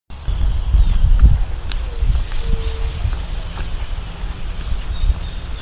White-tipped Dove (Leptotila verreauxi)
Province / Department: Buenos Aires
Location or protected area: Villa Rosa
Condition: Wild
Certainty: Recorded vocal